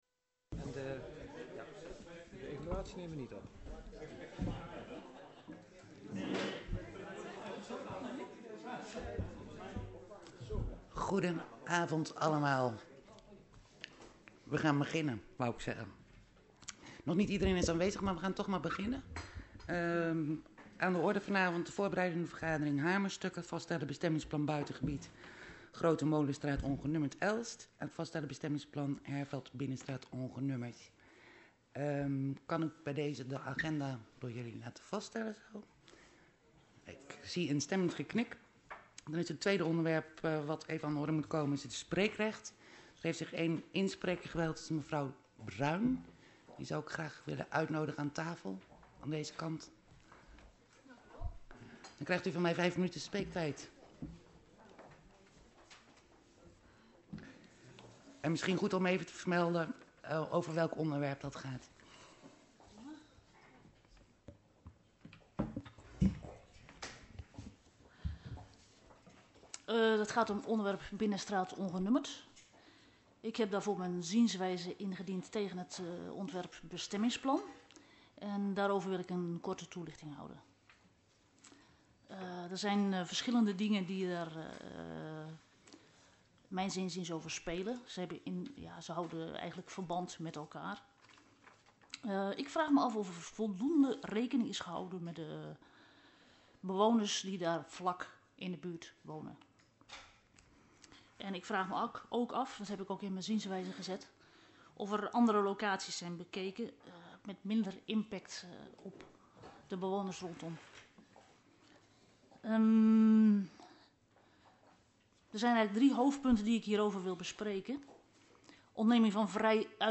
Locatie Hal, gemeentehuis Elst Voorzitter mevr. A.J. Versluis Toelichting Voorbereidende vergadering hamerstukken: a)Vaststellen Bestemmingsplan Buitengebied, Grote Molenstraat ongenummerd, Elst b)Vaststellen Bestemmingsplan Herveld, Binnenstraat ongenummerd 4) Alleen voor raads- en burgerleden: Onderlinge evaluatie .